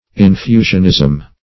Search Result for " infusionism" : The Collaborative International Dictionary of English v.0.48: Infusionism \In*fu"sion*ism\, n. The doctrine that the soul is preexistent to the body, and is infused into it at conception or birth; -- opposed to traducianism and creationism .
infusionism.mp3